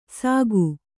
♪ sāgu